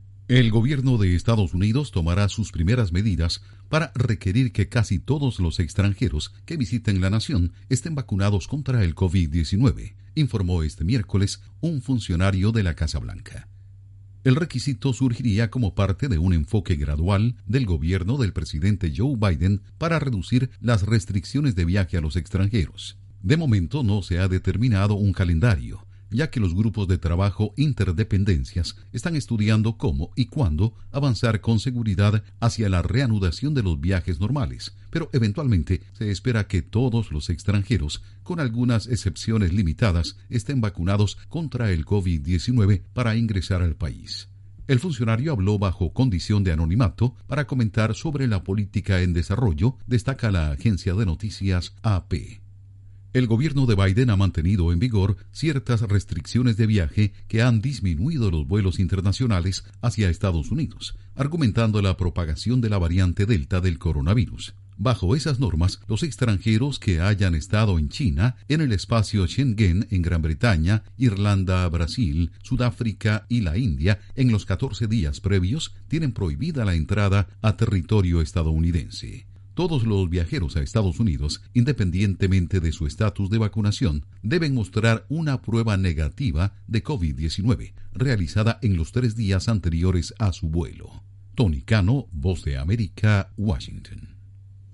EE.UU. planea exigir a visitantes vacuna contra COVID-19. Informa desde la Voz de América en Washington